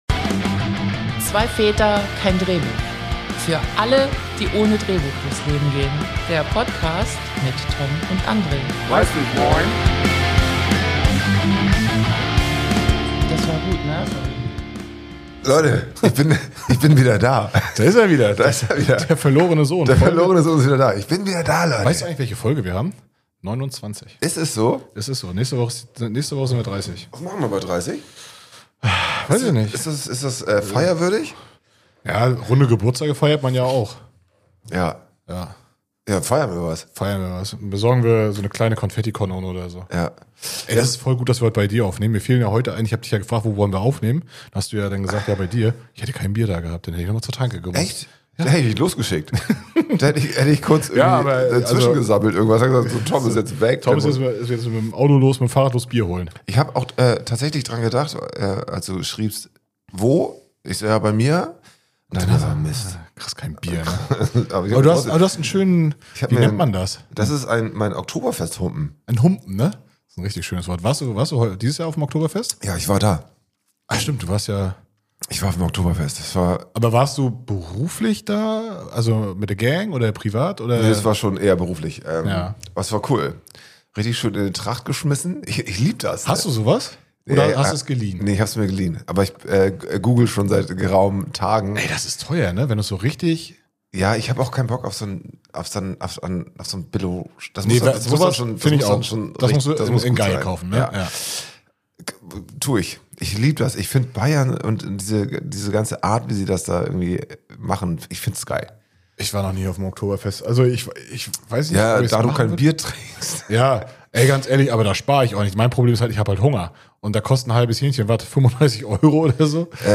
Beschreibung vor 2 Monaten In dieser Folge feiern die beiden Hosts nicht nur das nahende 30. Episoden-Jubiläum, sondern nehmen euch mit auf eine bunte Reise durch Oktoberfest-Erlebnisse, Festivalpläne und Dorf-Events. Es geht um teures Bier, wilde Geschichten von Security-Einsätzen und den ganz speziellen Charme kleiner Dorffeste.